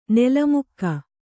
♪ nela mukka